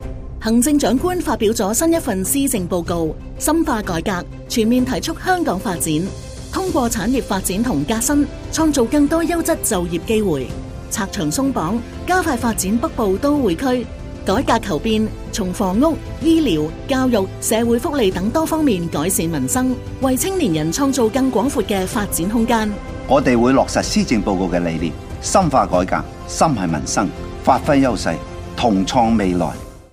電台宣傳廣播